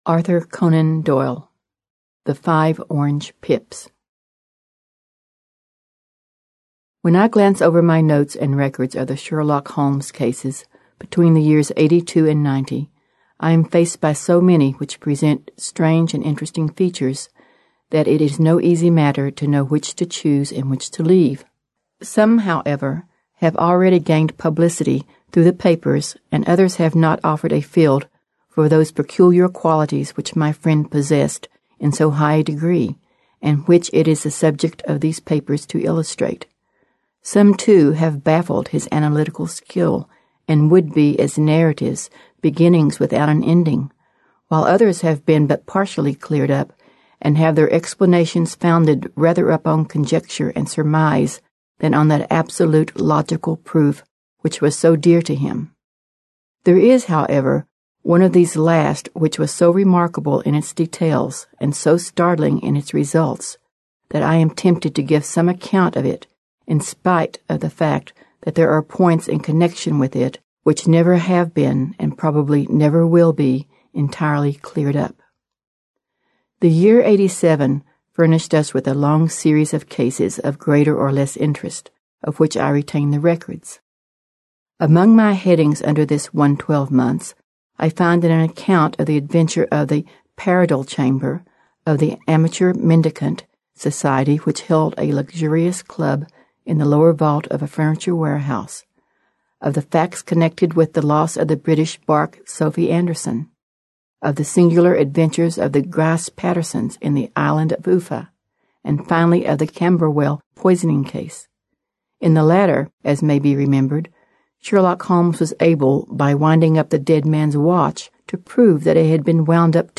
Аудиокнига Приключения Шерлока Холмса / The Adventures Of Sherlock Holmes. Collection | Библиотека аудиокниг